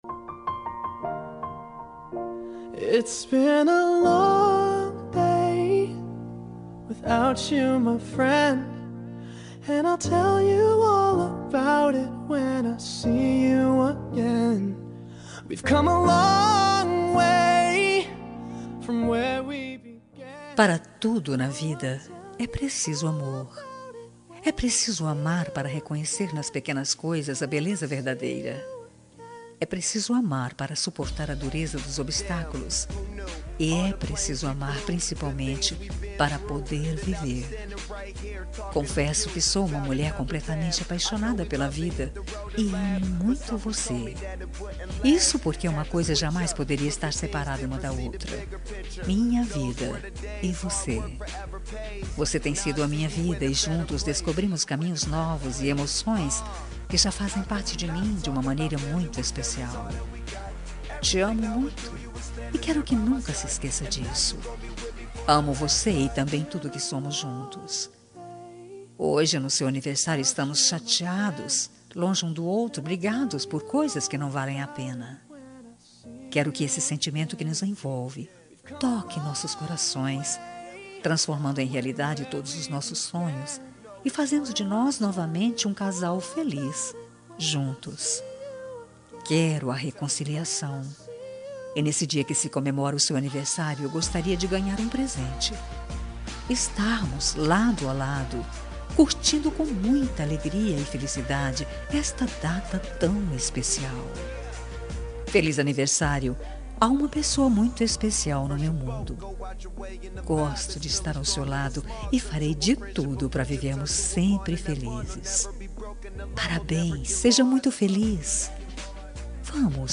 Aniversário Romântico com Reconciliação – Voz Feminina – Cód: 5458